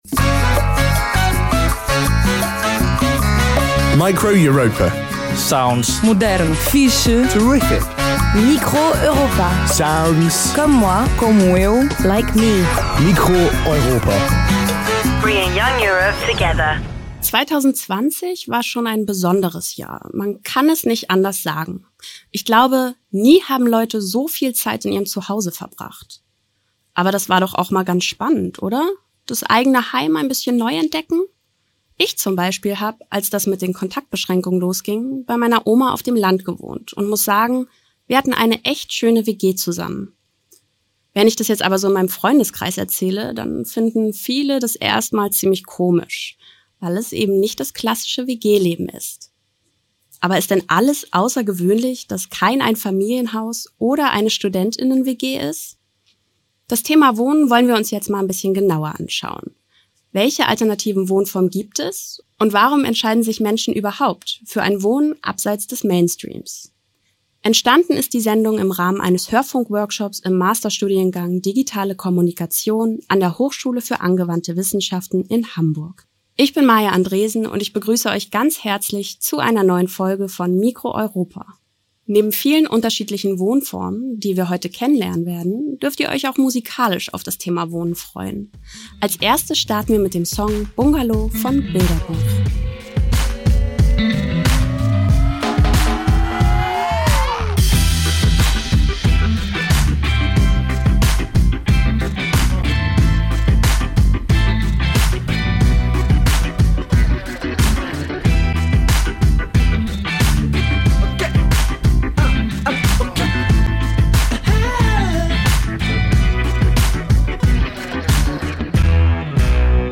Entstanden ist unsere Sendung im Rahmen eines Hörfunk-Workshops an der Hochschule für angewandte Wissenschaften (HAW) in Hamburg – im Herbst 2020 im Video-Chat unter schwierigen Corona-Bedingungen.